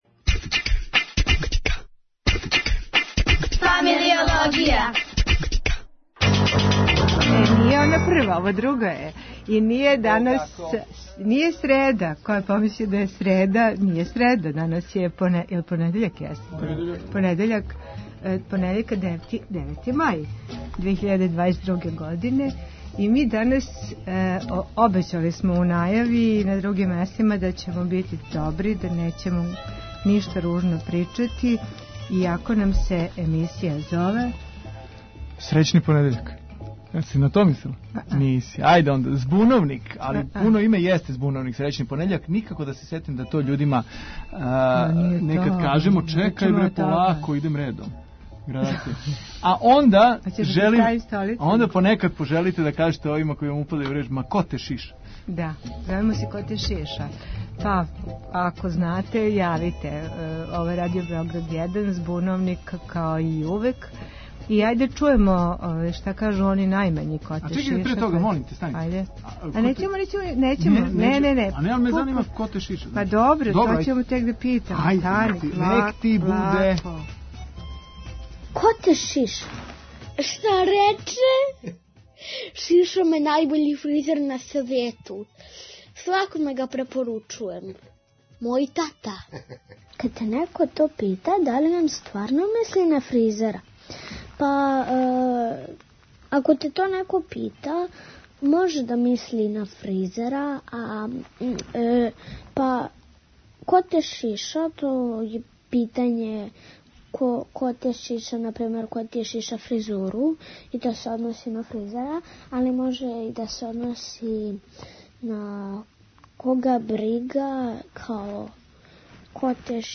Ако ставимо упитник, заиста нас интересује ко вас шиша (имаћемо једног мајстора у студију), а, ако ставимо узвичник... па, може да буде и безобразно...